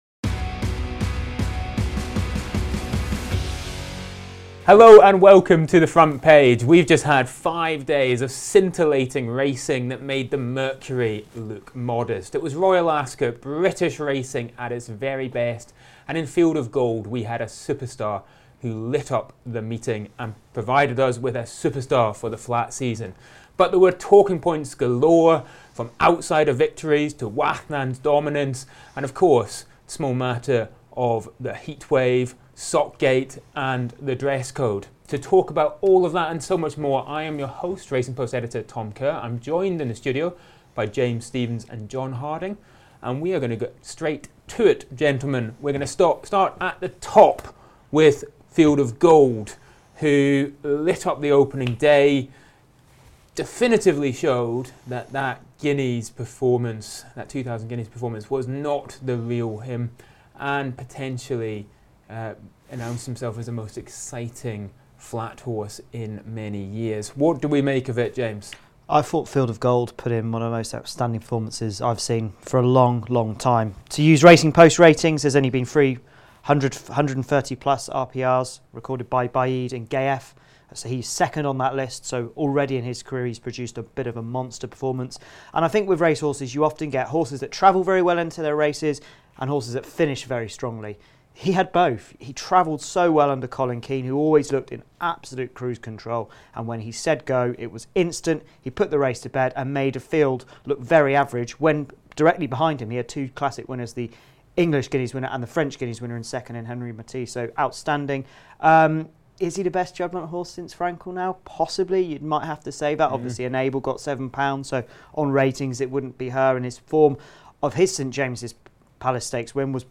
The Front Page panel unpacks a sizzling Royal Ascot 2025, at which Field Of Gold lit up the St James’s Palace, Wathnan Racing joined the sport’s elite and the mercury soared.